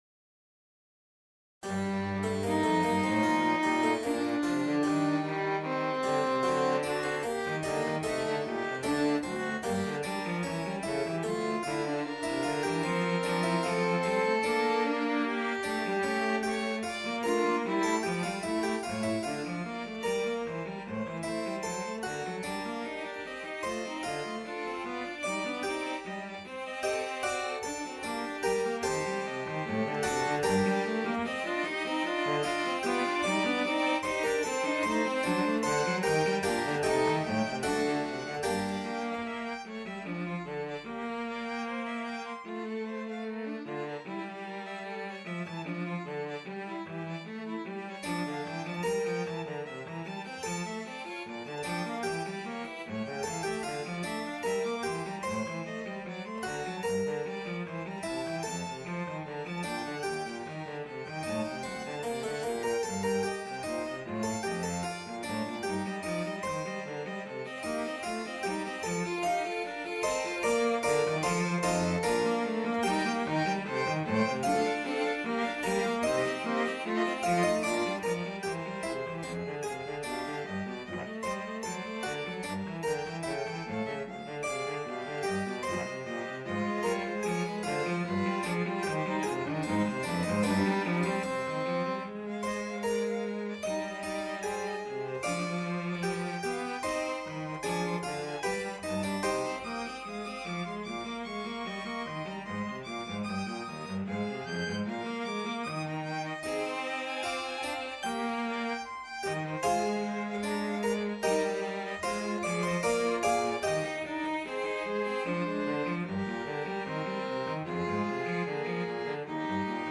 Free Sheet music for Violin-Cello Duet
Violin-Cello Duet  (View more Advanced Violin-Cello Duet Music)
Classical (View more Classical Violin-Cello Duet Music)